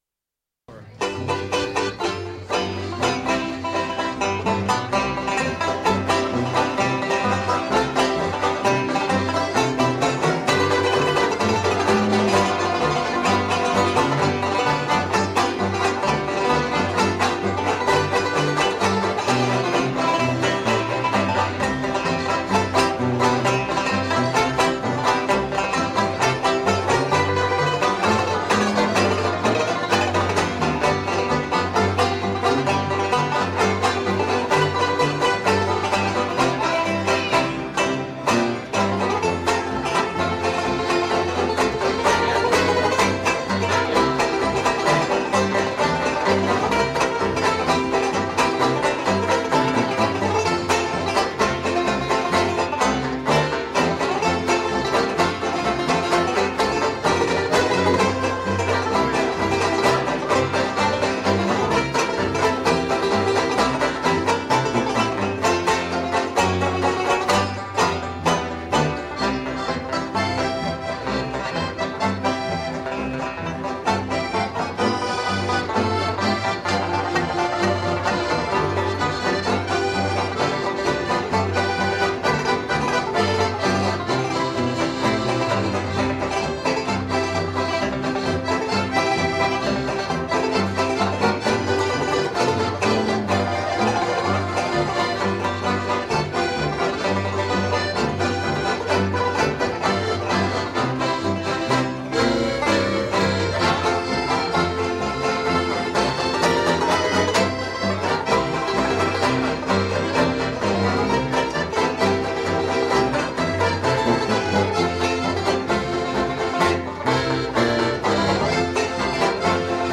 8-beat intro.
This song is in two keys: Part 1 is in C, Part 2 is in F.